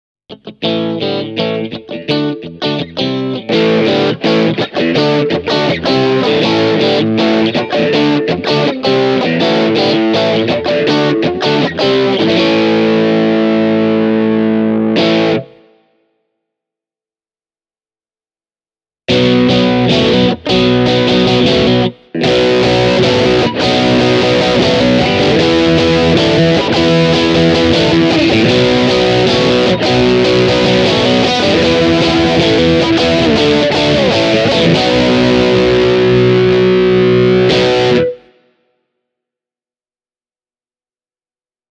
Mooer Green Mile (54 €) on firman Tube Screamer -tyylinen overdrive-särö.
Warm-moodissa Green Mile -pedaali toimii tavallisena TS-tyylisenä särönä, jolla on kermainen keskialue ja maltillinen kompressointi.
Tässä pätkässä Mooer Green Mile on Warm-moodissa. Soitan ensin Stratocasteria puhtaaseen kanavaan, ja sen jälkeen särökanavaan: